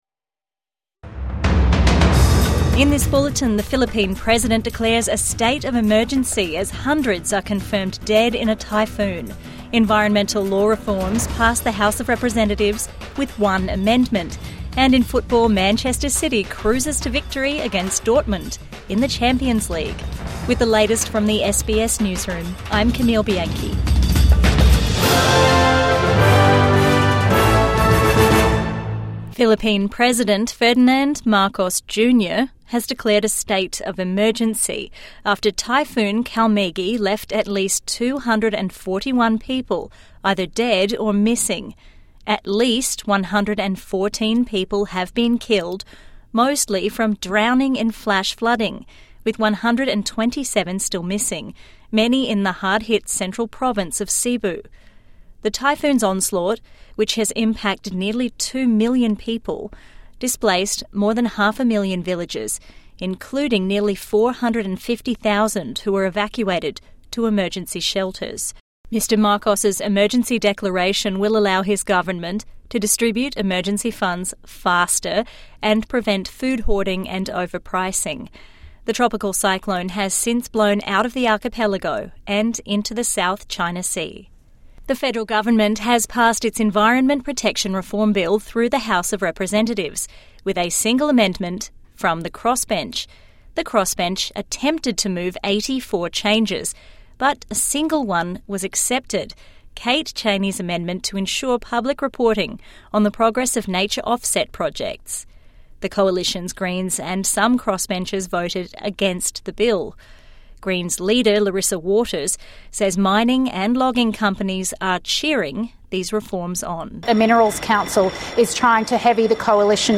State of Emergency declared after deadly Philippine typhoon | Evening News Bulletin 6 November 2025